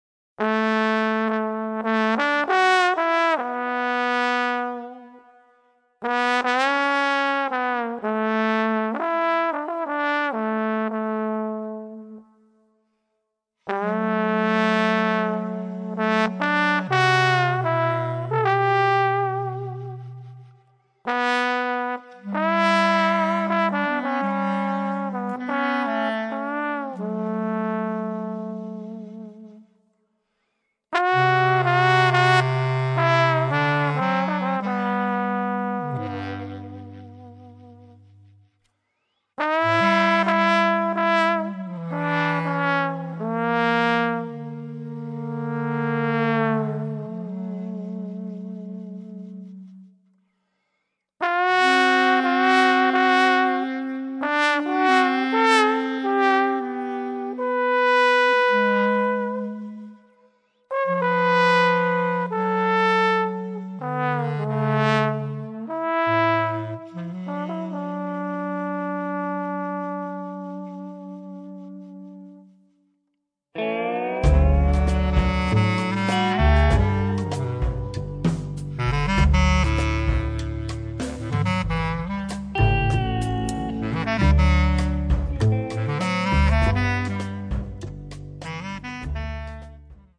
double bass, acoustic bass guitar
bass clarinet
trombone
drums
guitar on #3